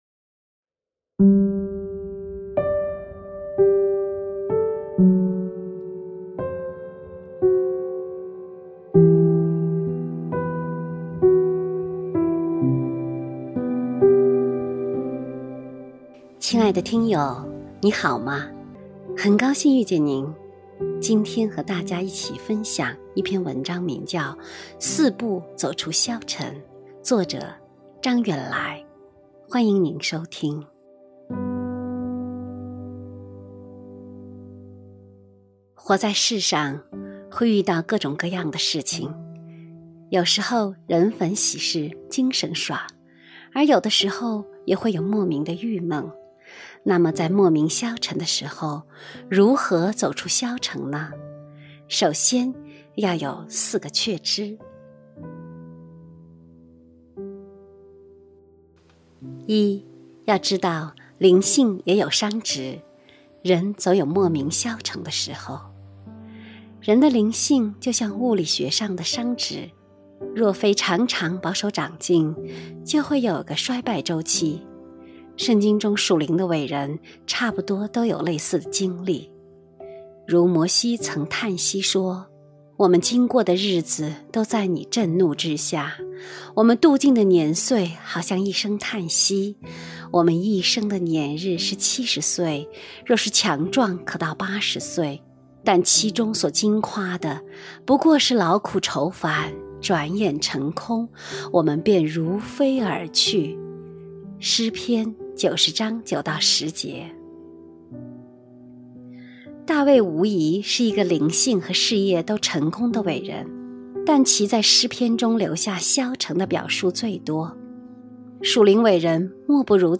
首页 > 有声书 | 灵性生活 > 四步走出消沉